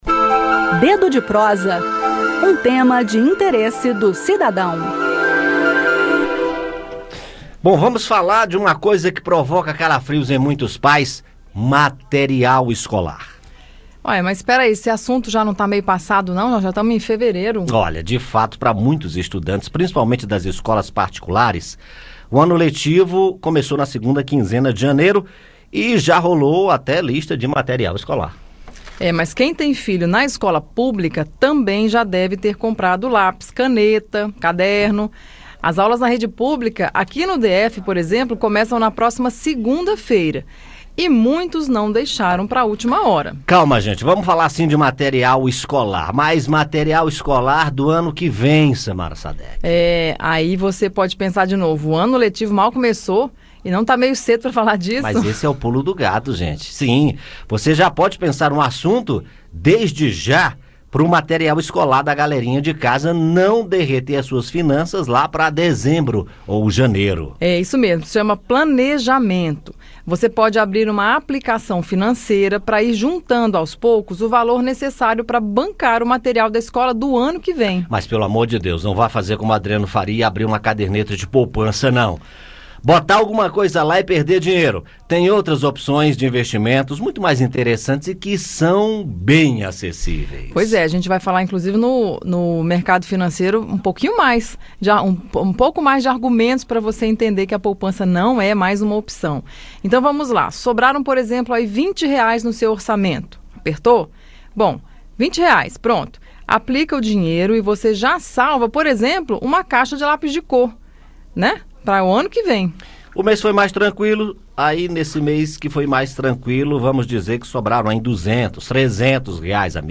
No "Dedo de Prosa" desta sexta-feira (7), o assunto é a compra de material escolar para a volta às aulas. Ouça o áudio com o bate-papo.